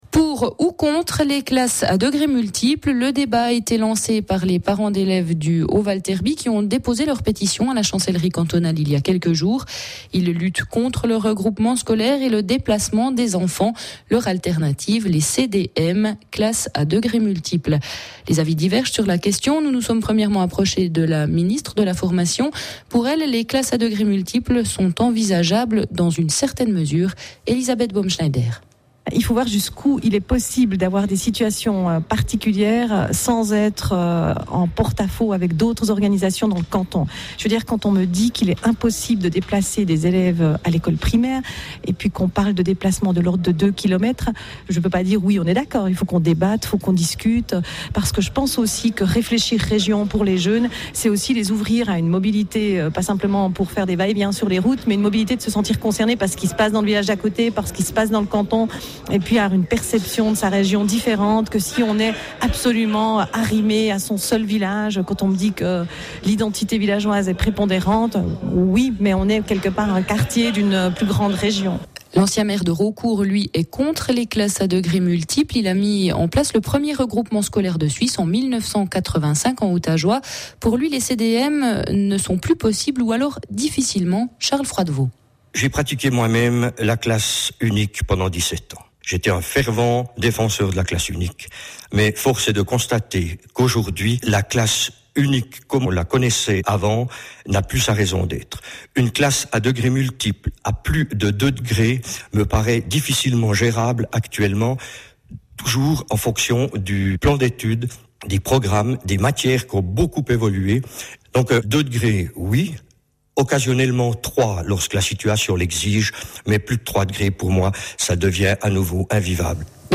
• Madame Elisabeth Baume-Schneider, Ministre de l’éducation de la RCJU